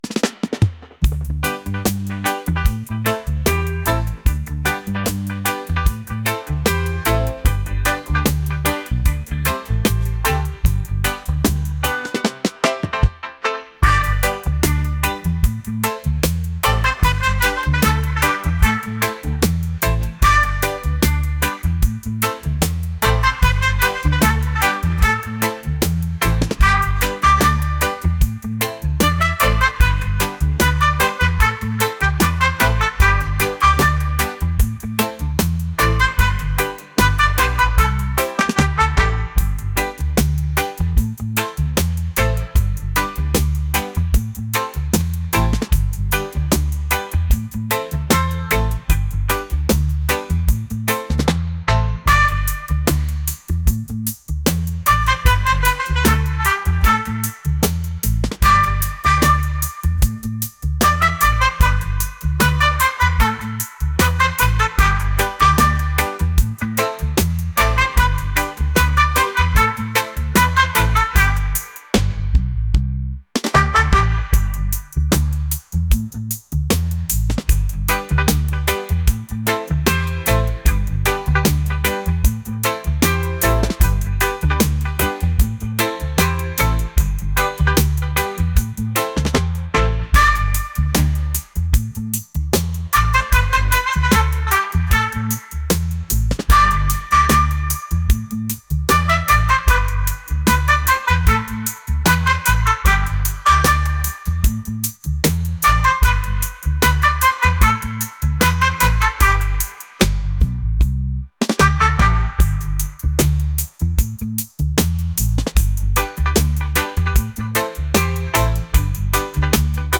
reggae | acoustic | lofi & chill beats